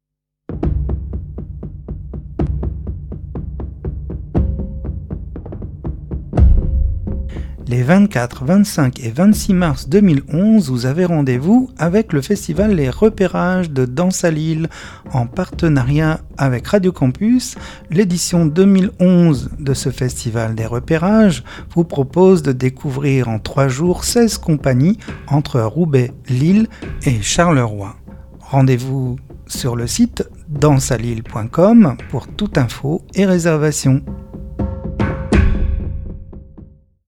Les_Reperages_2011_annonce_Radio_Campus.mp3